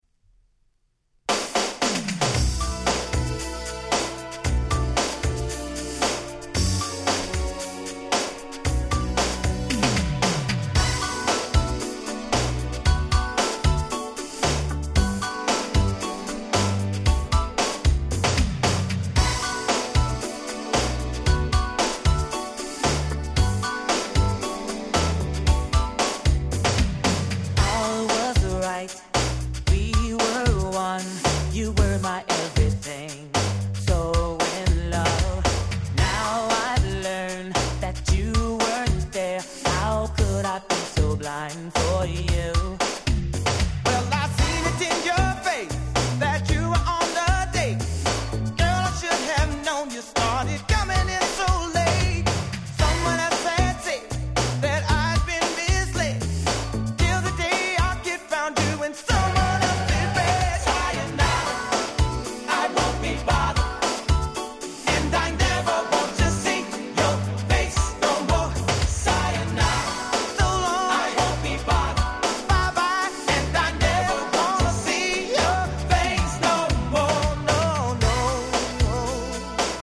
89' Big Hit R&B LP !!